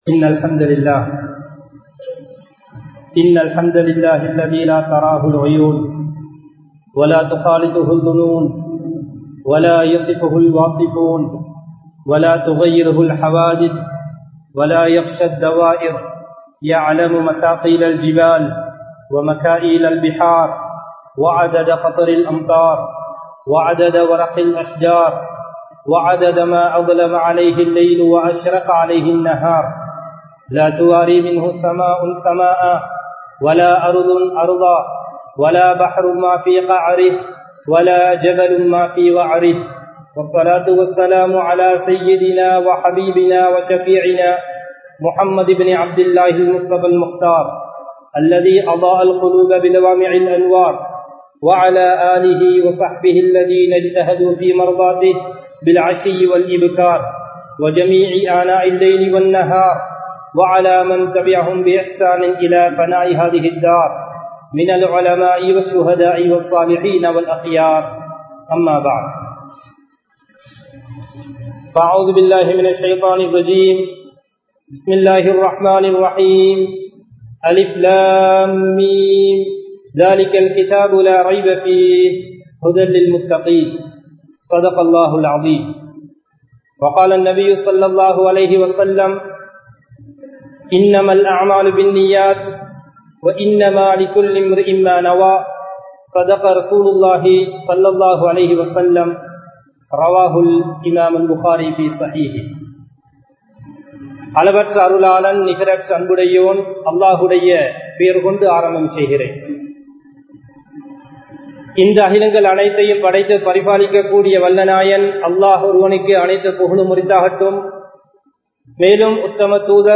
அல்லாஹ்வுக்கு விருப்பமான அமல் (The deed which prefers the creator of allah) | Audio Bayans | All Ceylon Muslim Youth Community | Addalaichenai